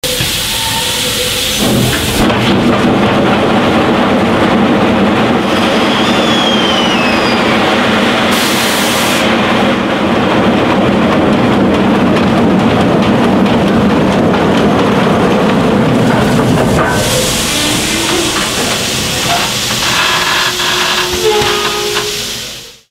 Steam Locomotive Wheel Slip - greasing the tracks - checking the running gear - NKP 765
One wheel grinding away on the track, sparking metal.